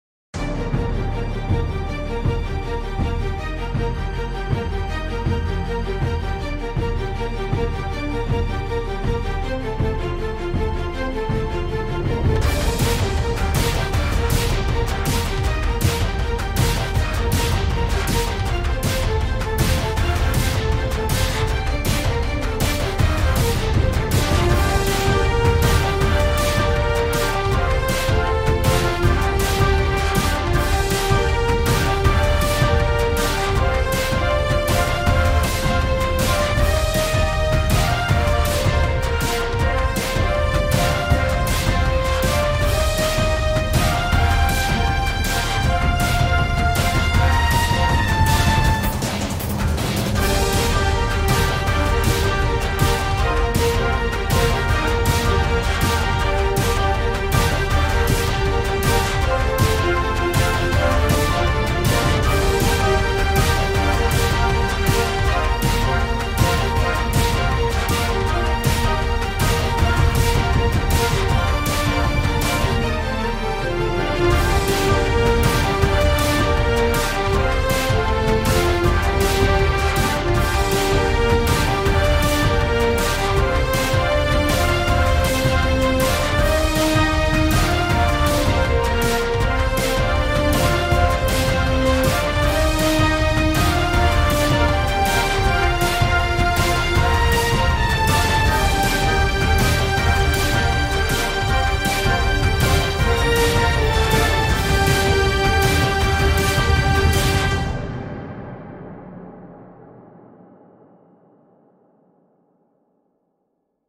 electronic genre